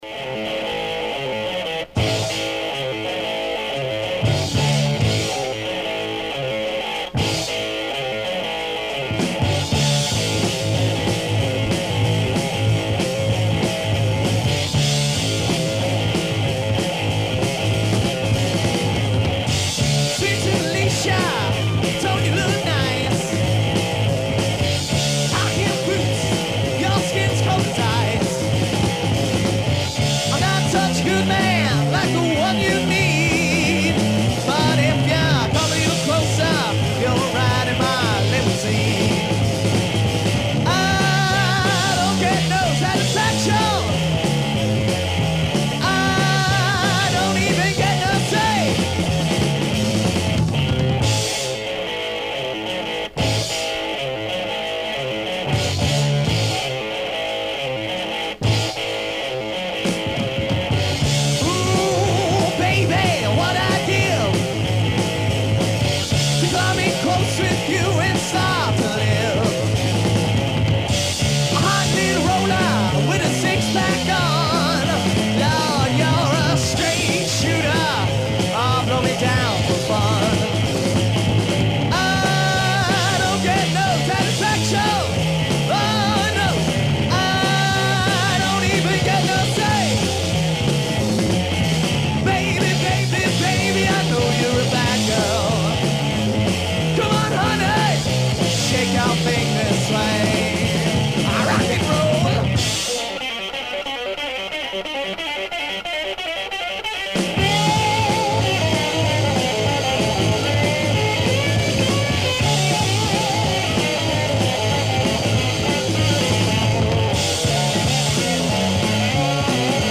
Oui, une petite session hard rock & heavy metal, comme si on était dans les années 80/90…